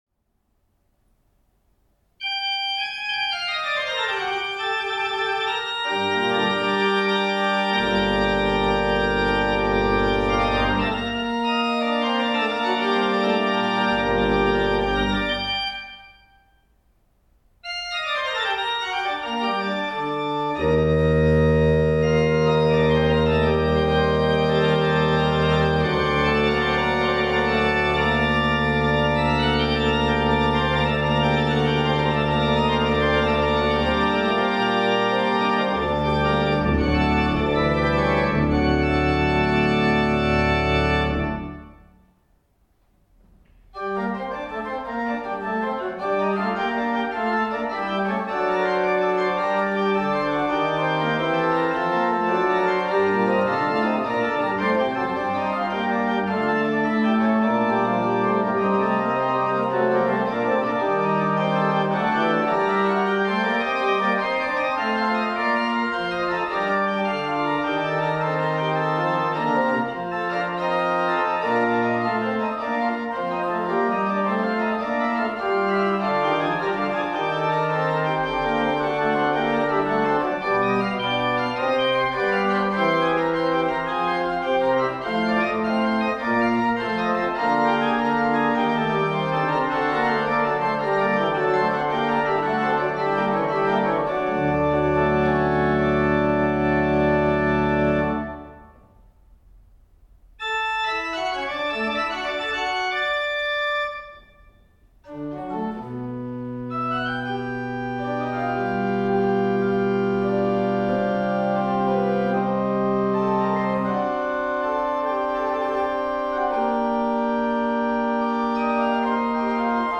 Bordenau Ev.-luth. St. Thomas-Kirche
Stimmung nach Bach-Kellner
Klangbeispiele dieser Orgel